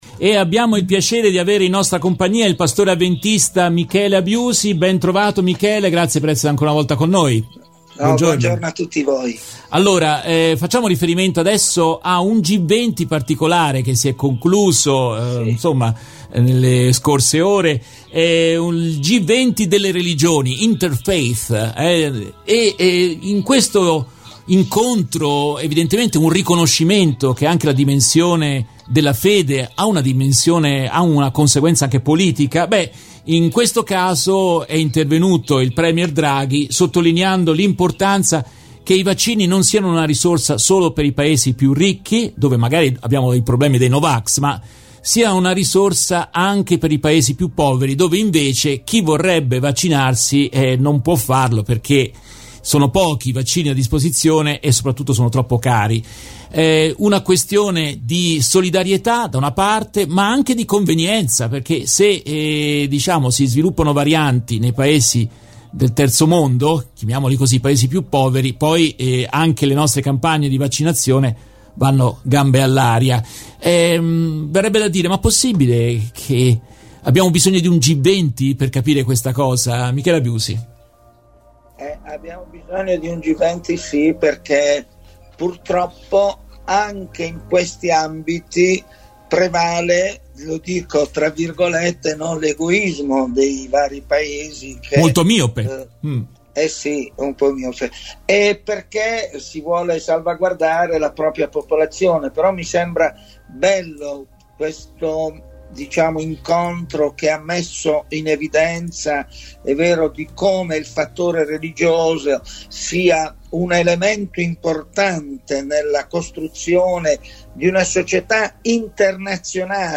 In questa intervista tratta dalla diretta RVS del 15 settembre 2021